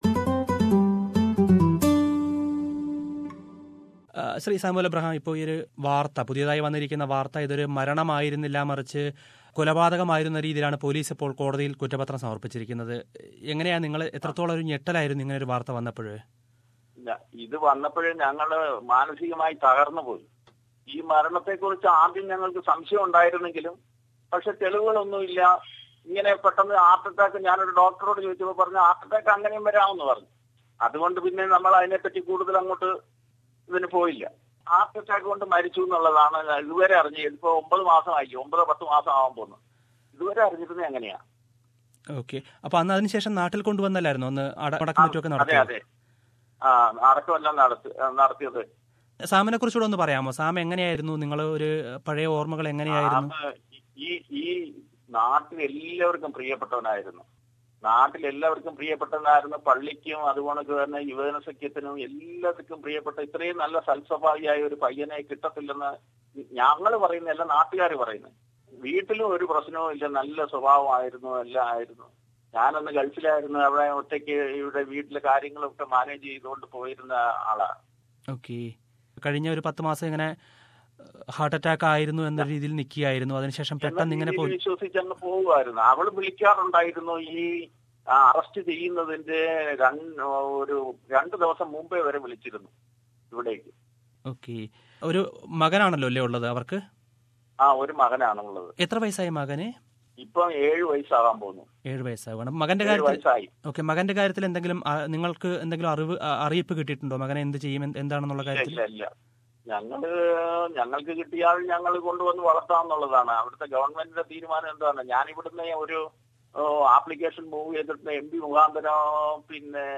അഭിമുഖം
(ശ്രോതാക്കളുടെ പ്രത്യേക ശ്രദ്ധയ്ക്ക് : ഈ അഭിമുഖത്തിൻറെ നല്ലൊരു ഭാഗം ഞങ്ങൾ എഡിറ്റ് ചെയ്ത് ഒഴിവാക്കിയിട്ടുണ്ട്.